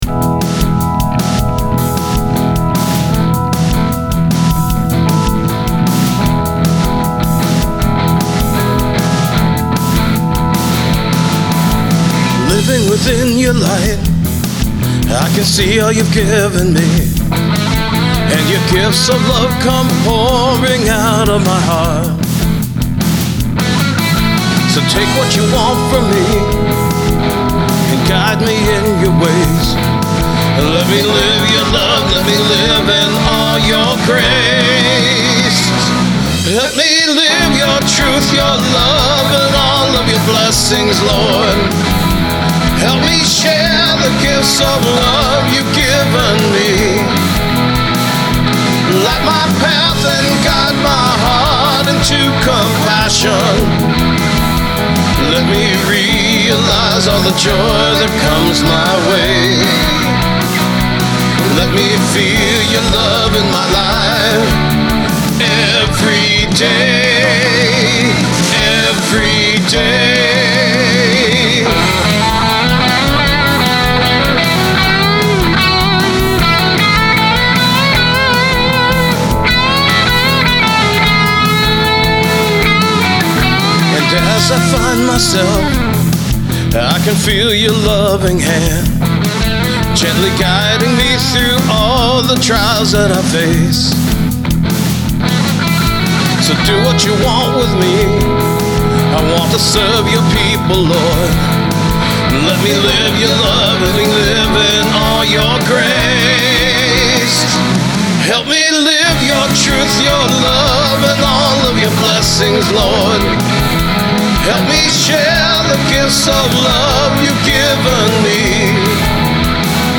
The aggressiveness was still there in the second channel, but it was much more tame and smooth. But overall, the tone was incredibly FAT!
I replaced the right channel rhythm and the lead part with the R8. The left rhythm part is my Strat in the neck pickup plugged straight into the VRX22. Note that I didn’t use any effects save a touch of compression and some reverb in the channel strip. So the guitar sounds you hear are just the guitar plugged into the amp; no EQ. The natural fatness is amazing! 🙂 Also, the amp was plugged into my Aracom PRX150-Pro attenuator, and recorded at loud conversation levels, so there’s no speaker breakup adding to the tone.